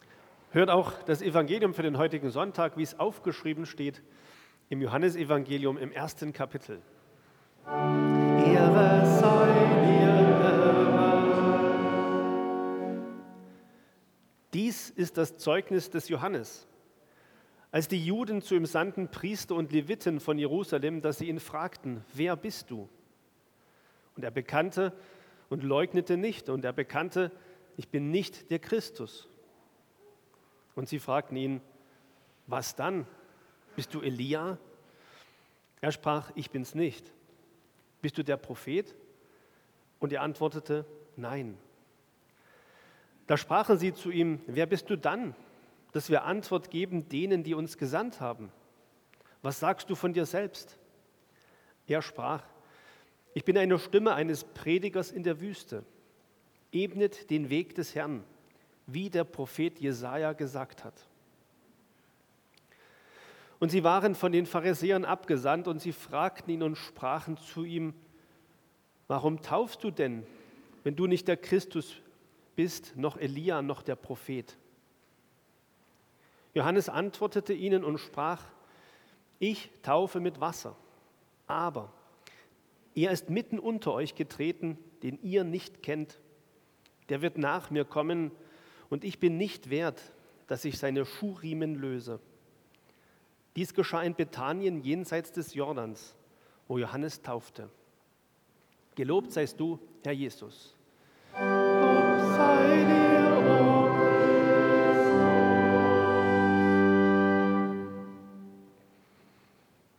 Evangeliumslesung aus Johannes 1,19-28 Ev.-Luth.
Audiomitschnitt unseres Gottesdienstes vom 4.Advent 2025.